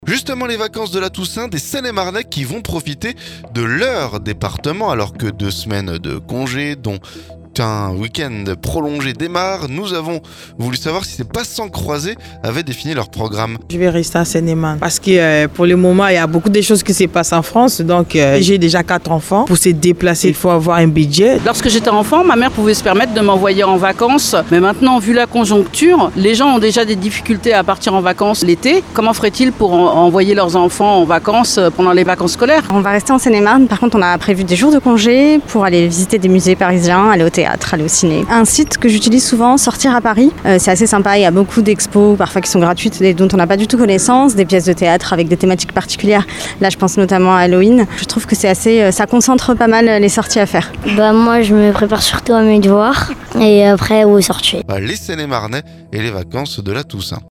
Vacances de la Toussaint : des Seine-et-Marnais qui vont profiter de leur département... Alors que deux semaines de congés, dont un avec un week-end prolongé, démarrent, nous avons voulu savoir si ces passants croisés avaient défini leur programme.